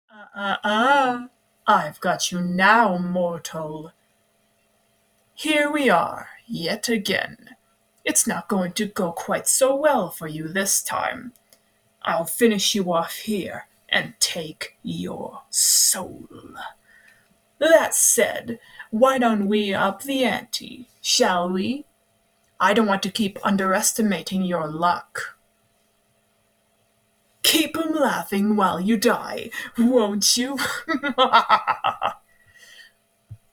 My voice Impression of Malak from Dark Deception [Crazy Carnevil]
P.S: It’s passable in my opinion…for a girl XD